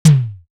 Sizzle Kit Low.wav